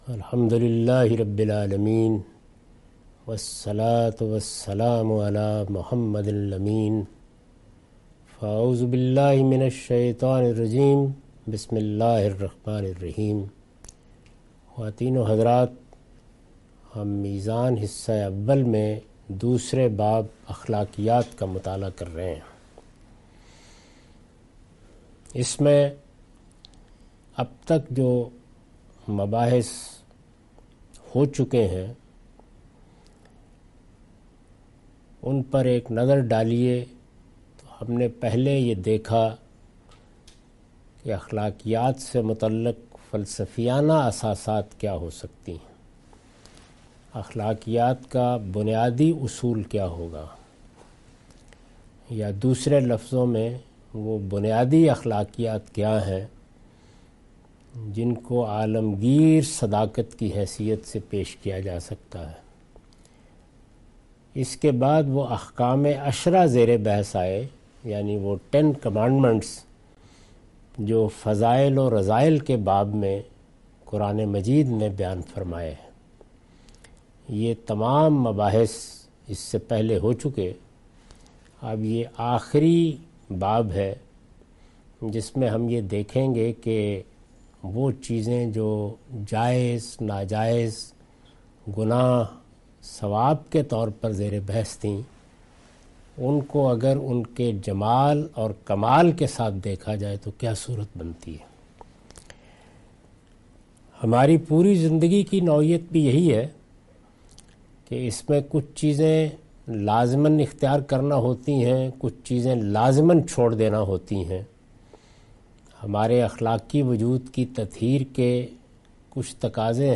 Meezan Class by Javed Ahmad Ghamidi.